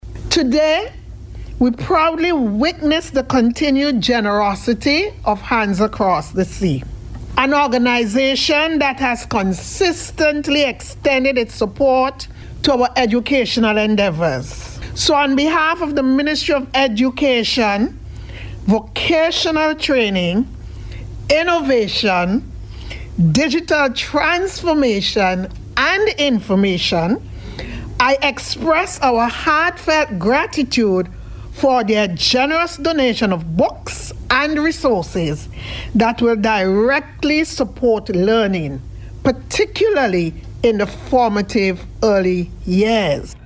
Speaking at the handover ceremony at the National Public Library on Friday, Chief Education Officer Kay Martin Jack praised Hands Across the Sea for its longstanding contribution to literacy development in schools.